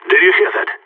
Game: Counter-Strike - Global Offensive SFX (PS3, Windows, Xbox 360) (gamerip) (2012)